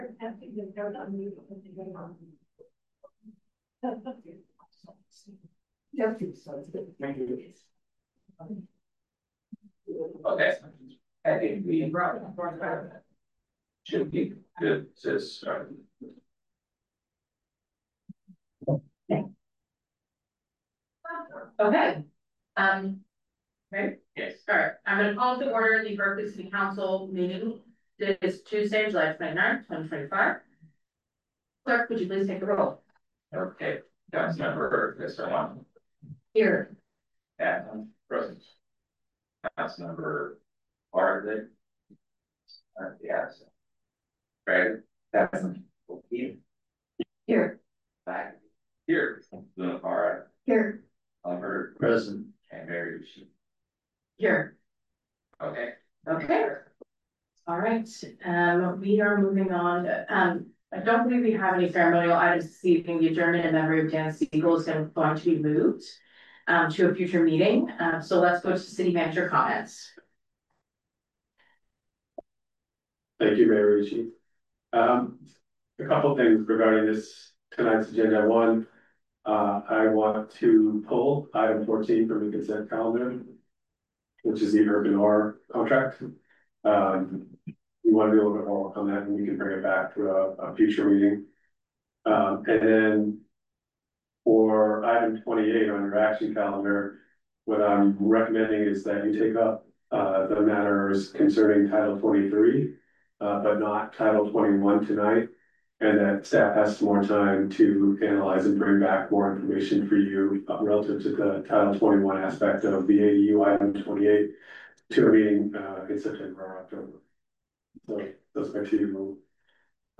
This meeting will be conducted in a hybrid model with both in-person attendance and virtual participation.